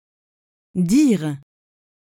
🎧 Dire pronunciation
diʁ/, which sounds like deer.